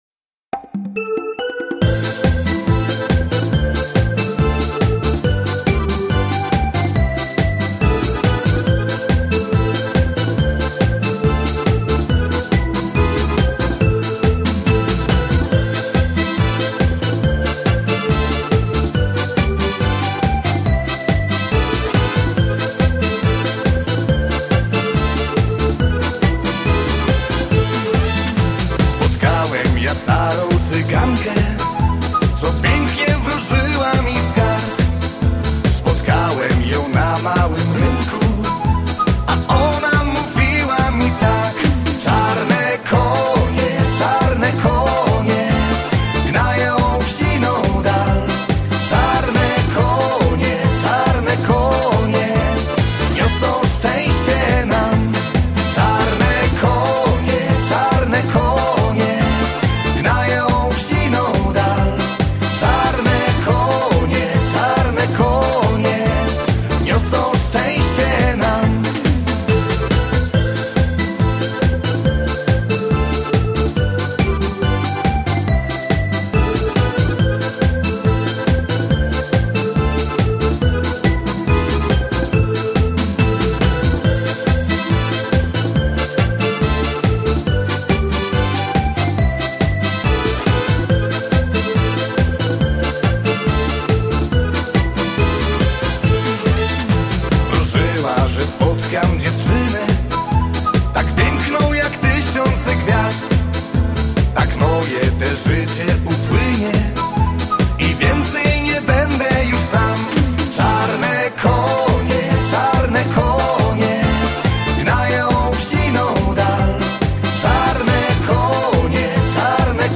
disco polo biesiadne